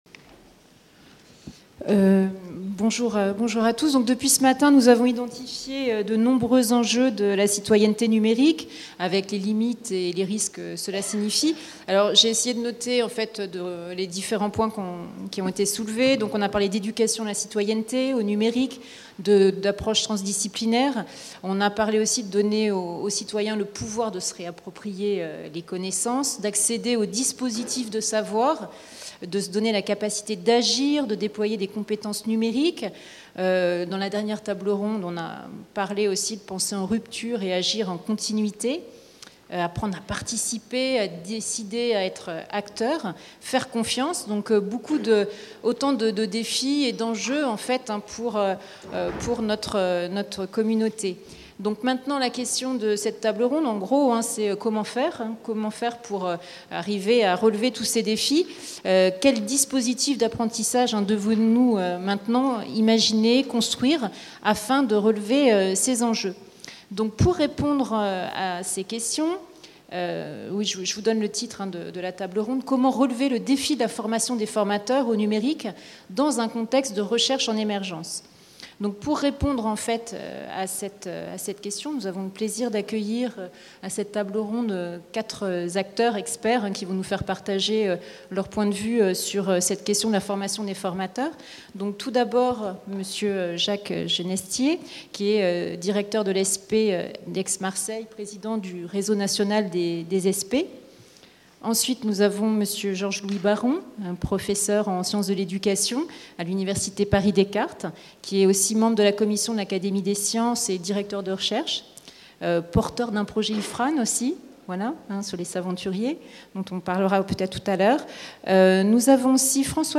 Colloque de La Conférence des présidents d’université (CPU) Université de Caen Normandie 30 novembre 2016
Table ronde n°4